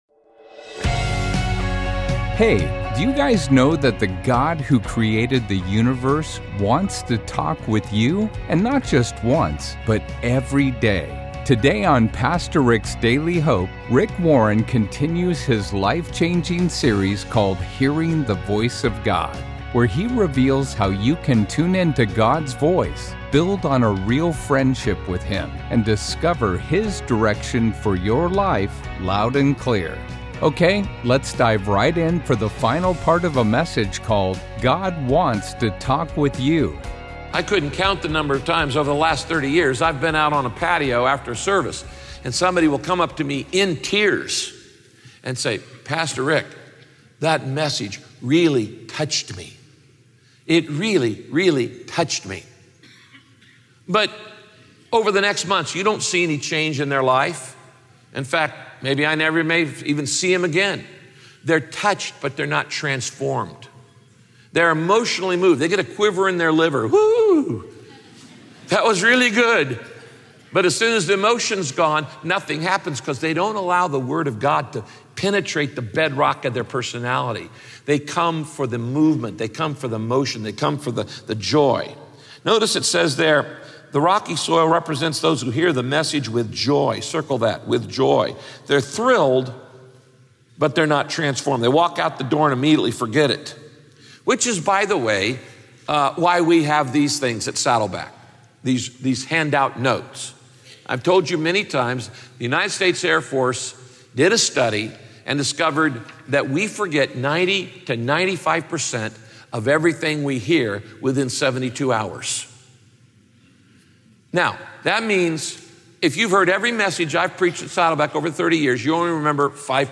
Like a healthy plant, you need to grow deep roots and keep the weeds at bay. Listen to this message by Pastor Rick and learn how to develop deep spiritual roots that help you bear fruit and eliminate the distractions that keep you from hearing God.